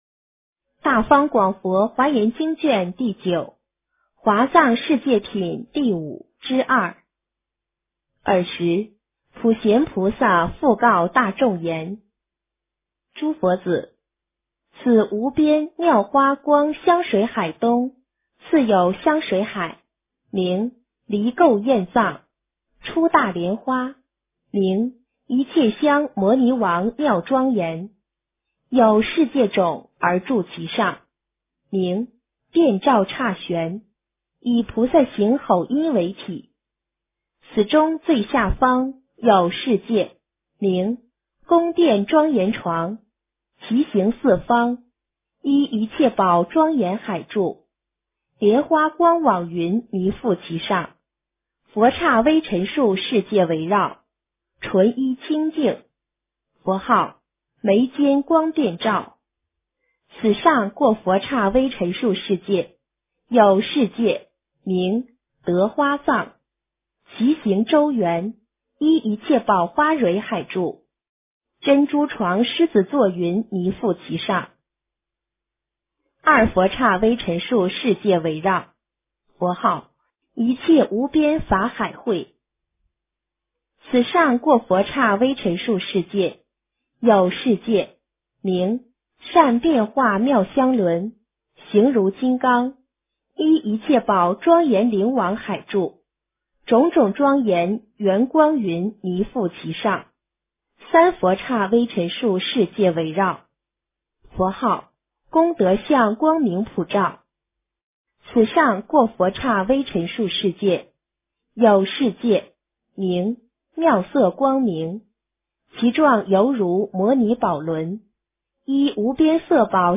华严经09 - 诵经 - 云佛论坛